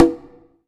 CONGA 13.wav